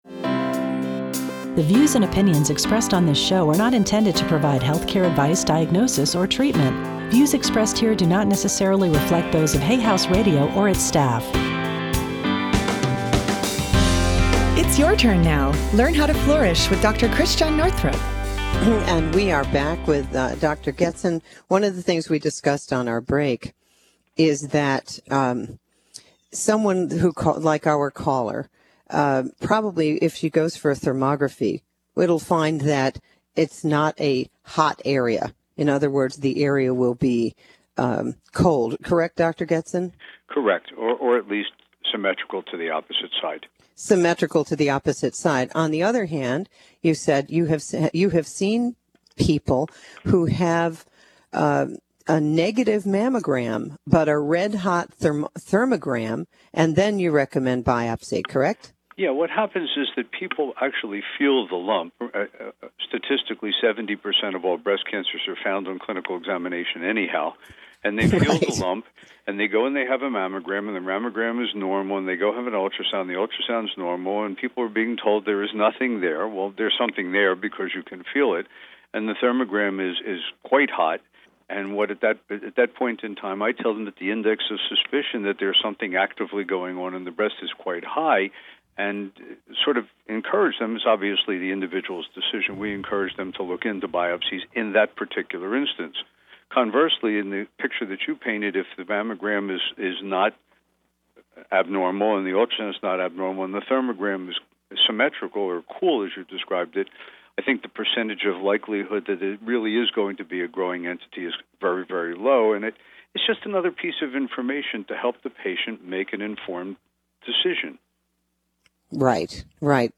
Hay House Radio interview Track 02